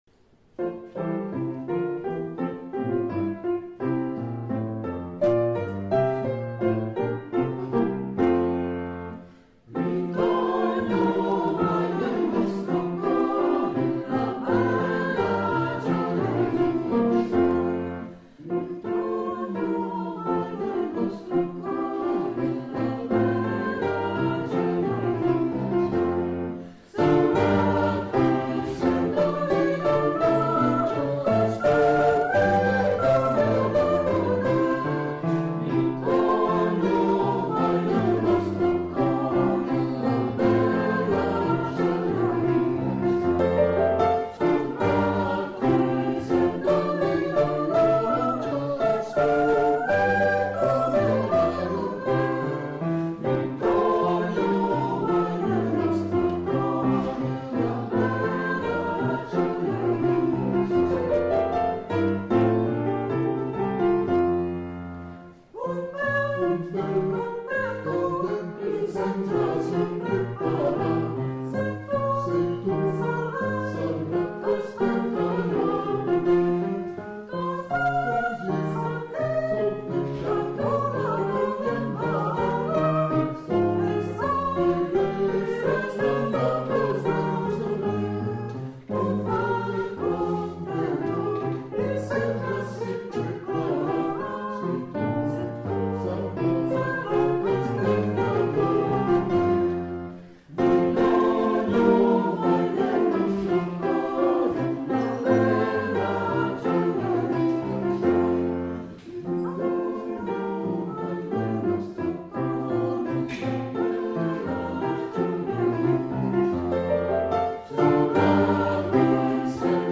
Ensemble vocal PlayBach Lambersart
audition à la Maison Jean XXIII